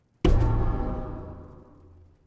AlertSound3.wav